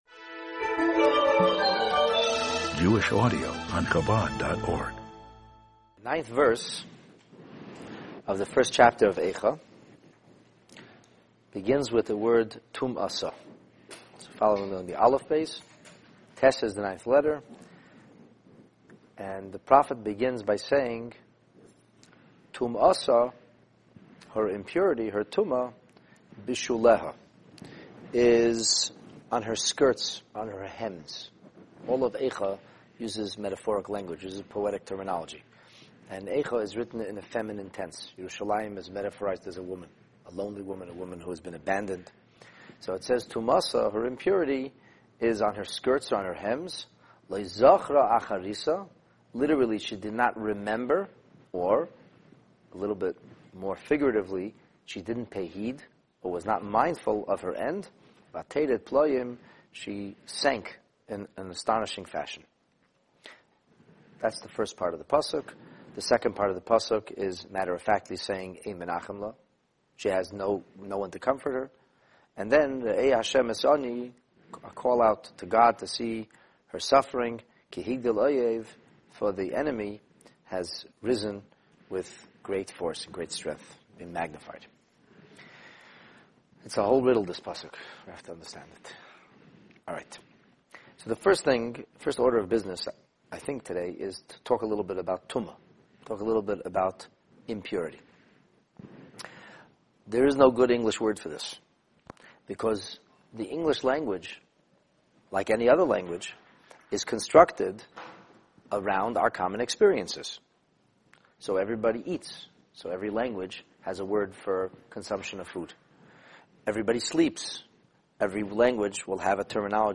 This text-based class on Megillat Eicha focuses on verse 9 of the first chapter.